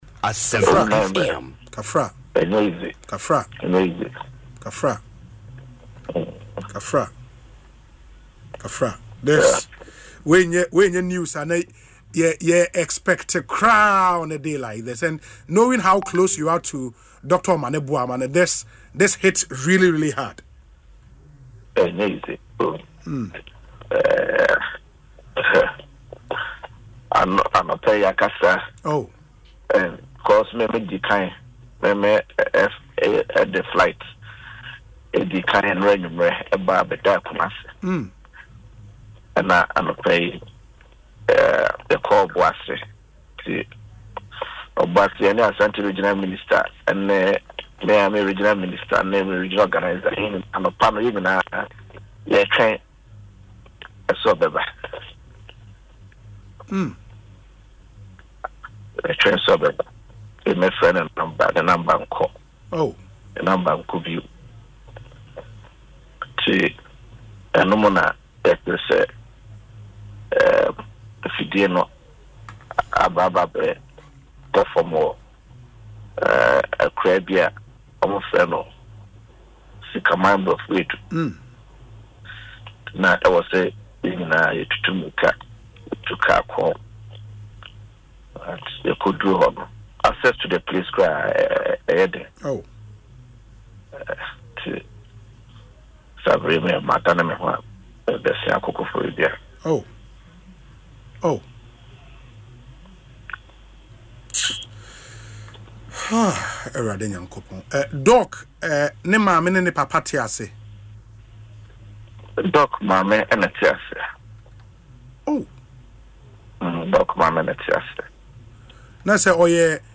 In an interview on Asempa FM’s Ekosii Sen, Dr. Kevor, who also serves as Acting Director-General of the National Information Technology Agency (NITA), said he was devastated by the tragic news.